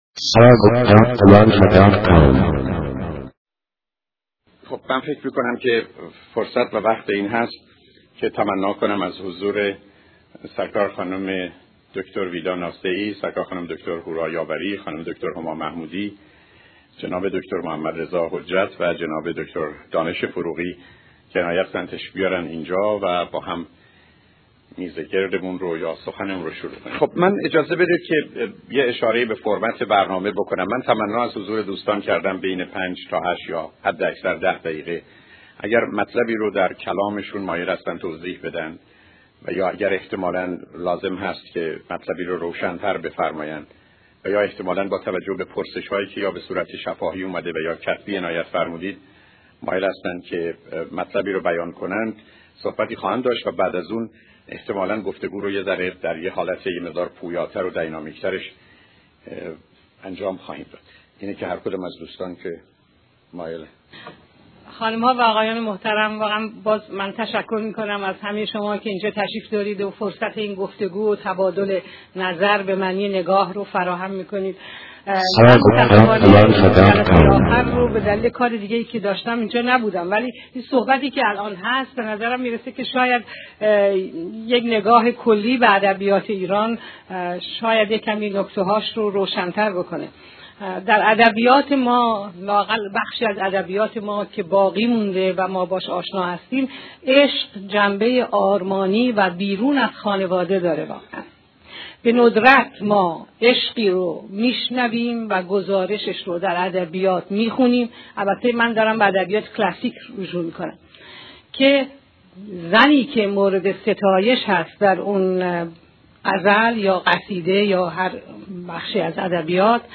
سمینار ازدواج و خانواده